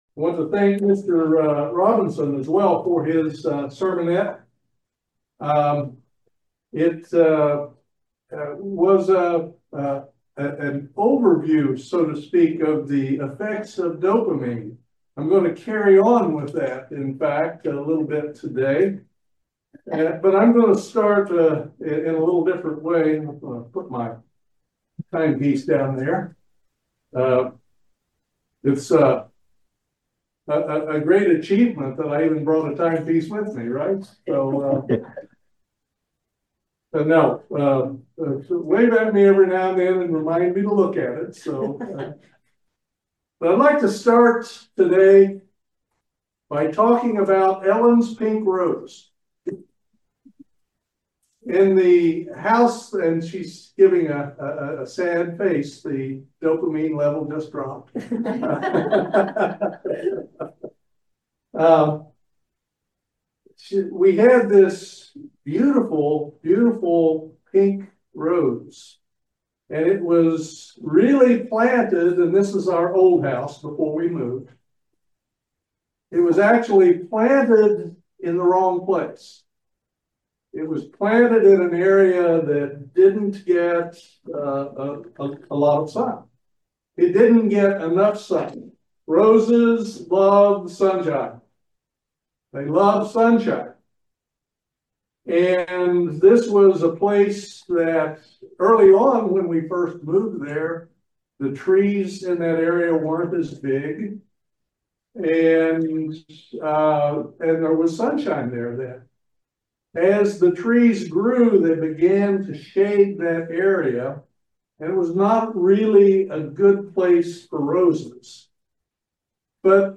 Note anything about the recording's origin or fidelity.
Given in Lexington, KY